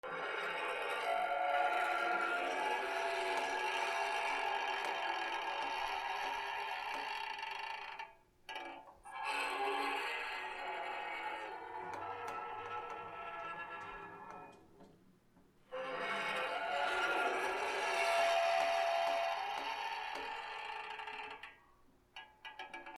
きしみ トイレ(ゆっくり)
/ K｜フォーリー(開閉) / K51 ｜ドア－きしみ